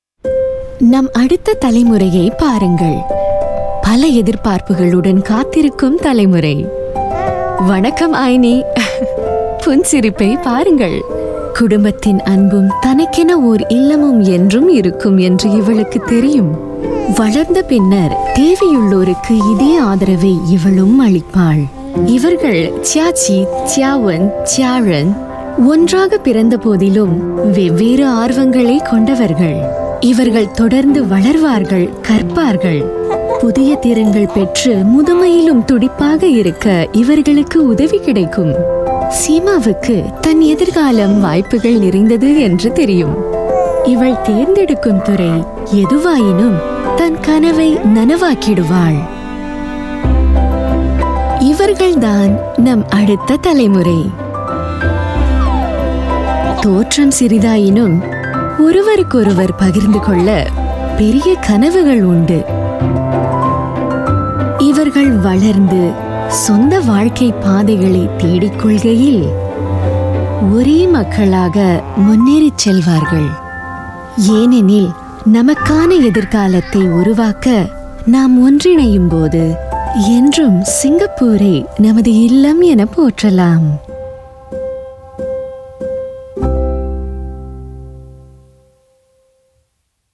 Voice Samples: MCI Moving Singapore Forward